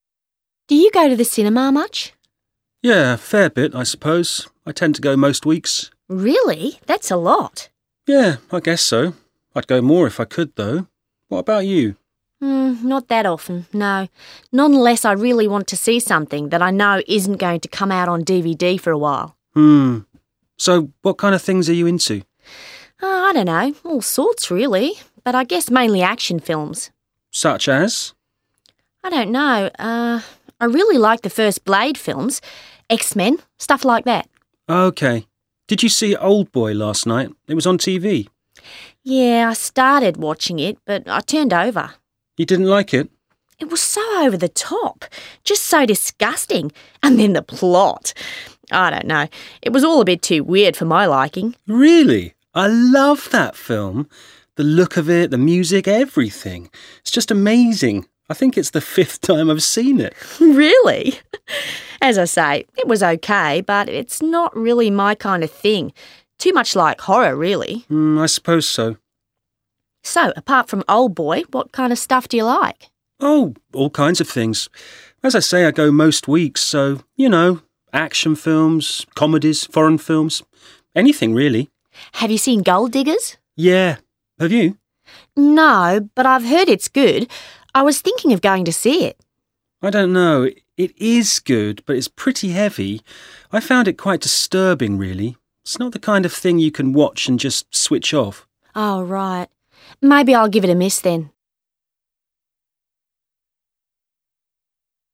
A. You are going to hear two people talking about films. Listen and take notes on how their tastes are similar — and how they differ.